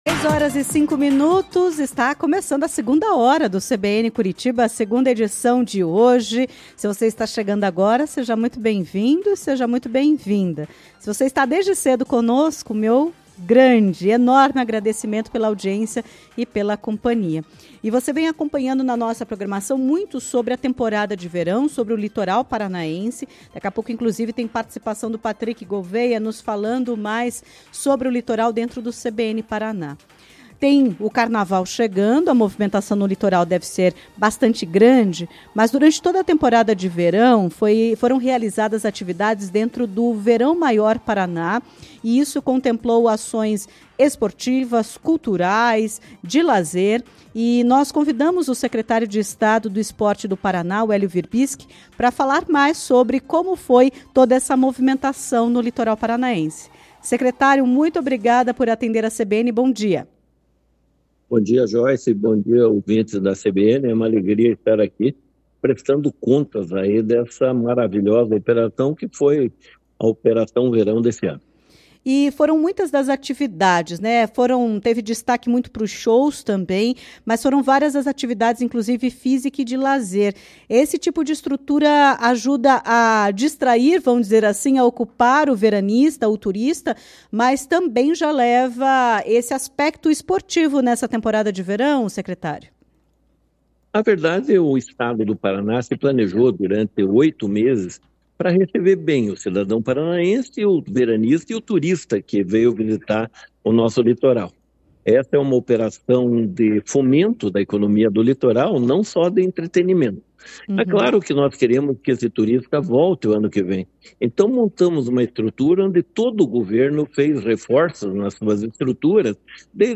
Em entrevista à CBN Curitiba nesta terça-feira (25), o secretário de Estado do Esporte, Helio Wirbiski, fez um balanço das ações nas últimas semanas e revelou que as atividades para a próxima temporada de verão já estão sendo planejadas.